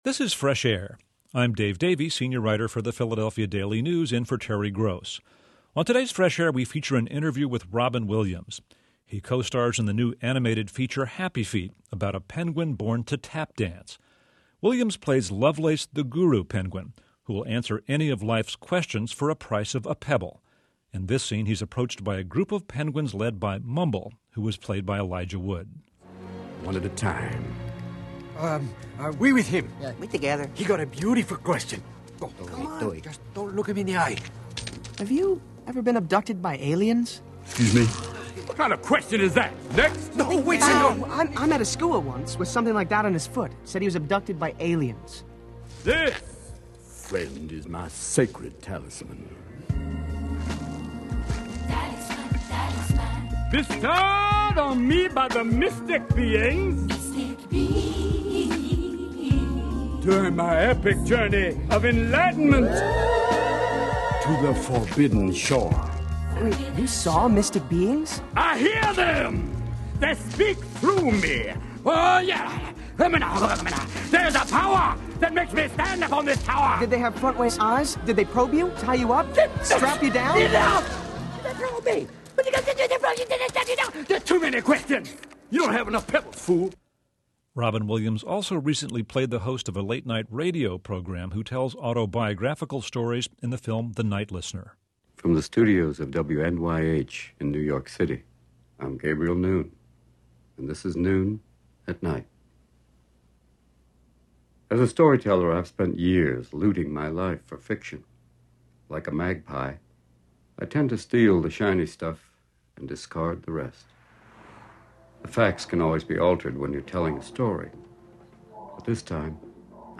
Click on the link here for Audio Player – NPR – Fresh Air with Terry Gross – Robin Williams interview – November 24, 2006 – NPR